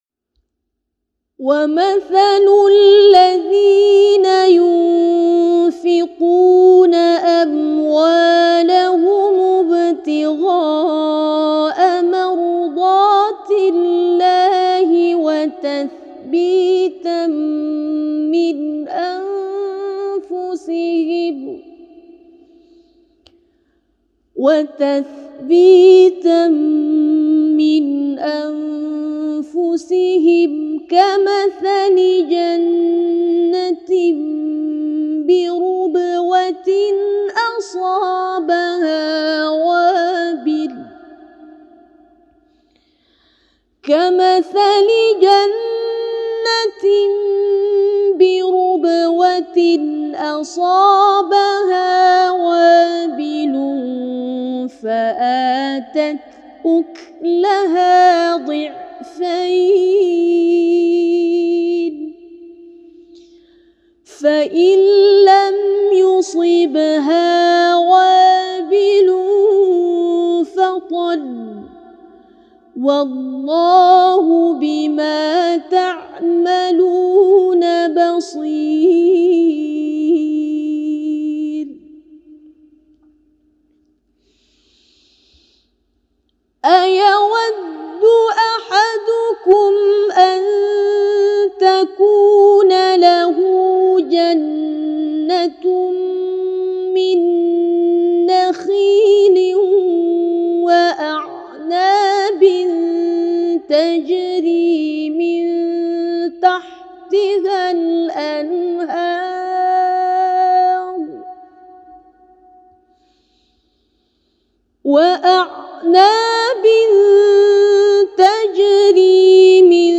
Qira’at Imam Abu Amru Riwayat As-Susi